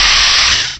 cry_not_bisharp.aif